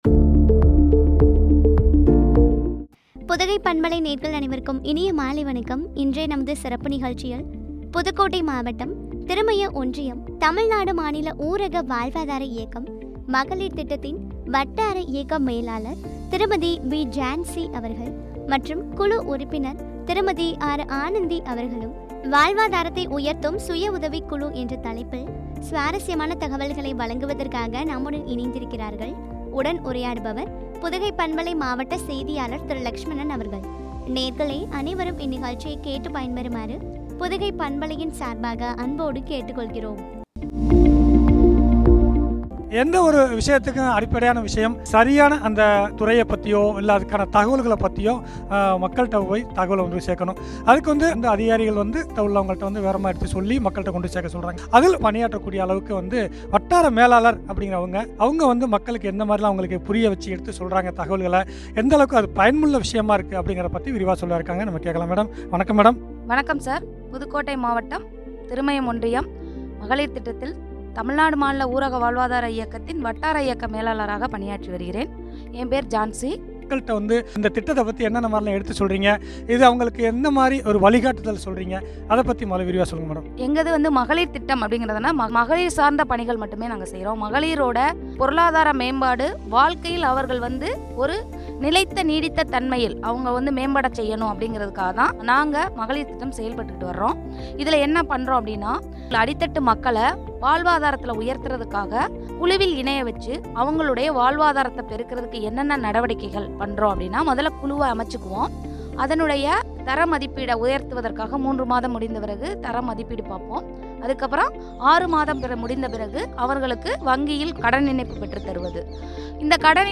வாழ்வாதாரத்தை உயர்த்தும் சுயஉதவிக்குழு பற்றிய உரையாடல்.